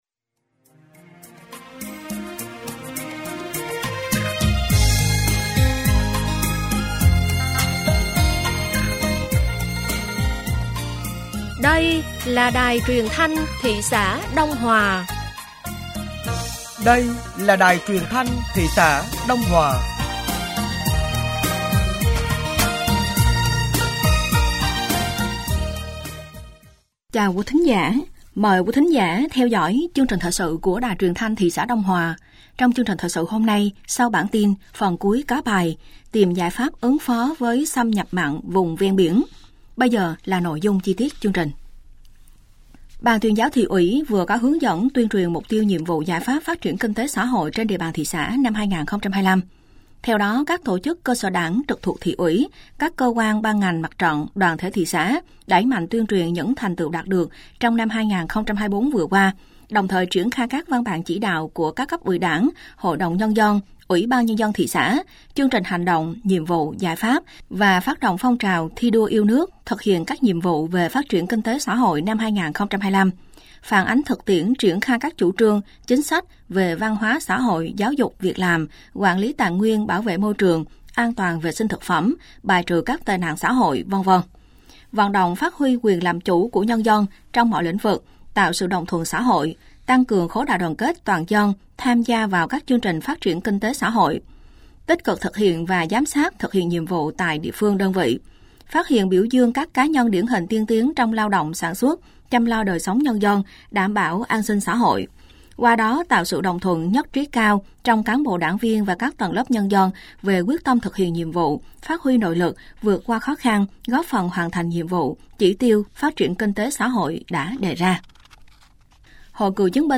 Thời sự tối ngày 17 và sáng ngày 18 tháng 02 năm 2025